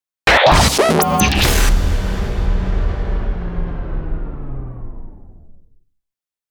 FX-017-IMPACT-COMBO.mp3